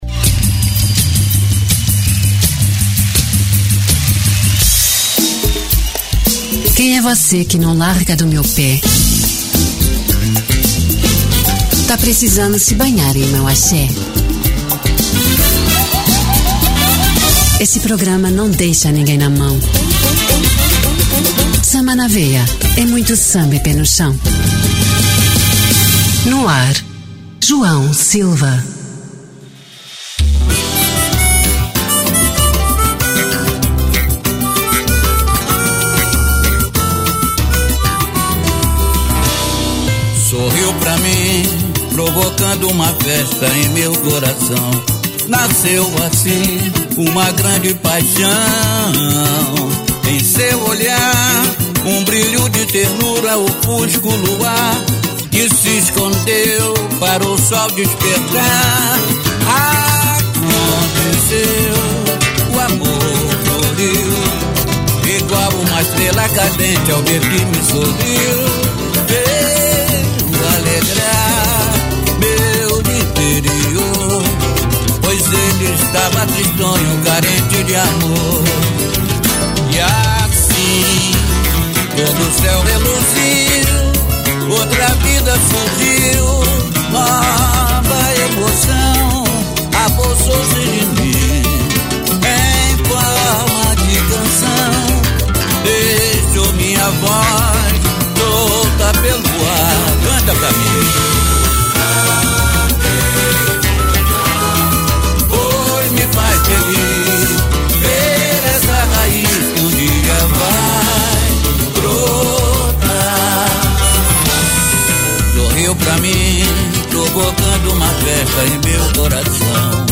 Samba de Raíz
Muito Samba e pé no chão!